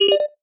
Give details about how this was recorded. ▶ Fixed - Added in a check for every time there is a collision between the parent-ator and another entity 2) Sound almost unhearable due to distortion ▶ Fixed - Changed audio to 16 bit mono 3) The Parent-ator model rezzes pointing at the user's face.